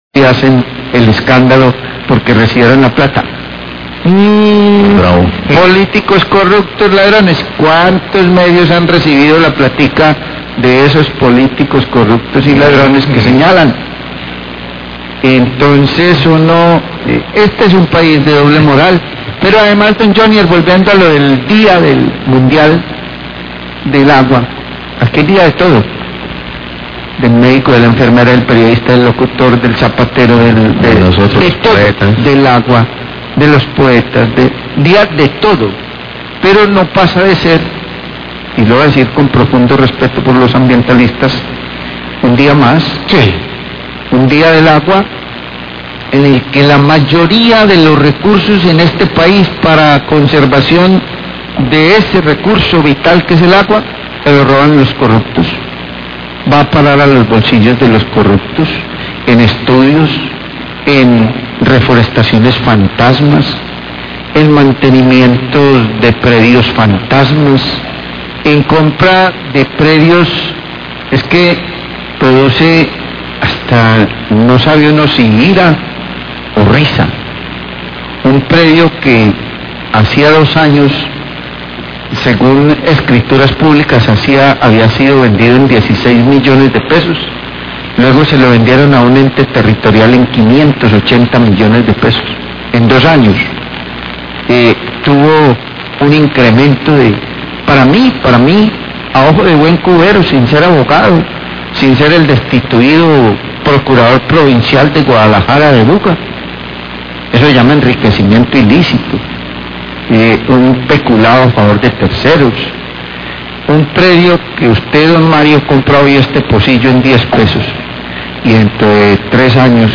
Radio
A propósito del Día Internacional del Agua, la mesa de trabajo discute sobre la corrupción alrededor de la conservación y mantenimiento de acueductos e instalaciones.